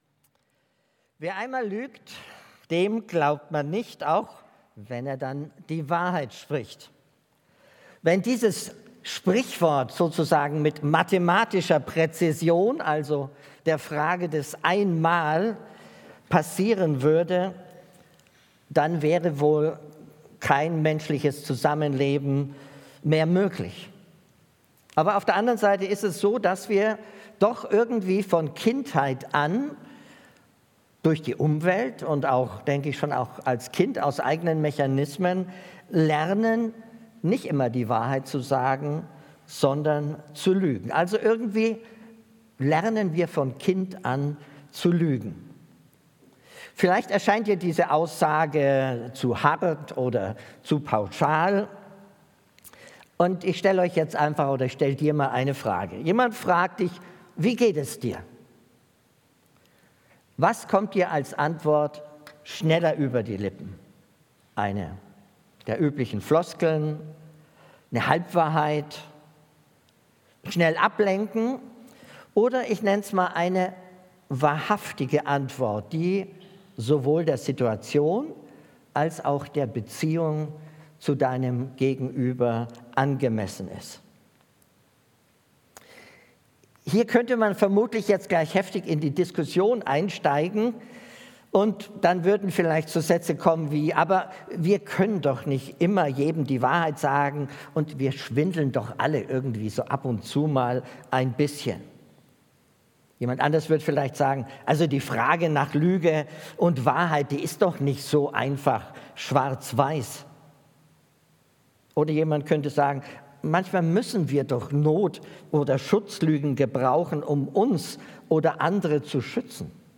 Predigt Thema: " Wer einmal lügt, dem glaubt man nicht ..."